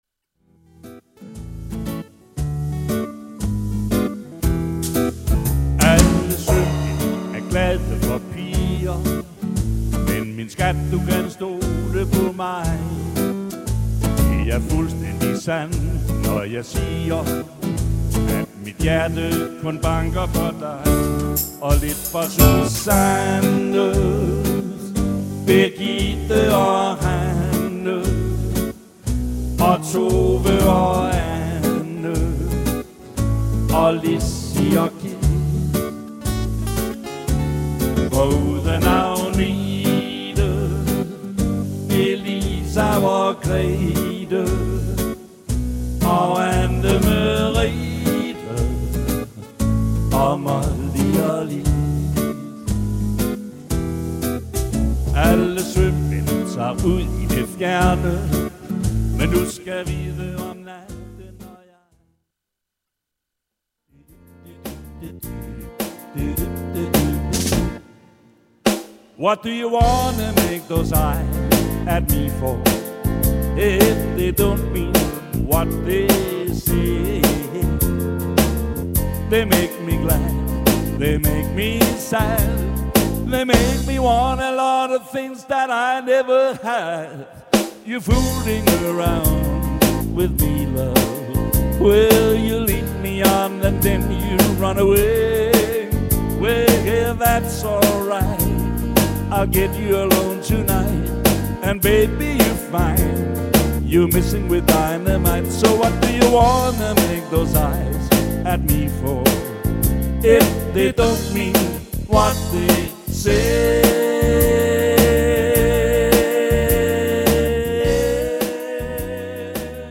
MUSIKKEN FRA DENGANG – UNDERHOLDNING MED GUITAR OG SANG